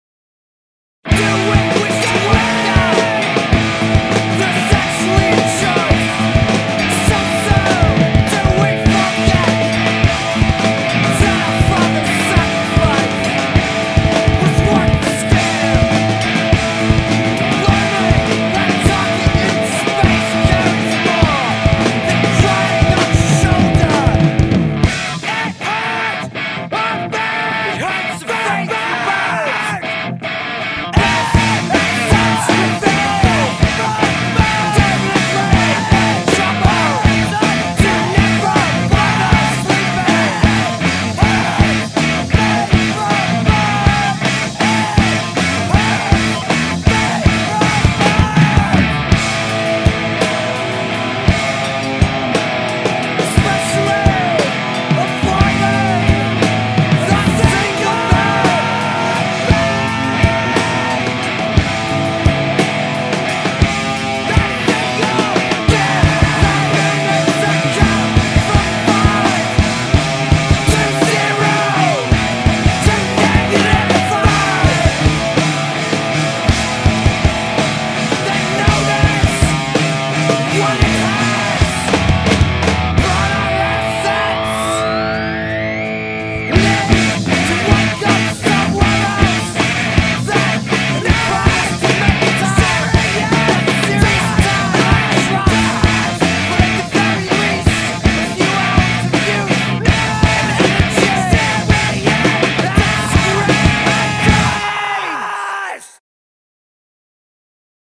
You get to hear the band rock, then mellow out a bit.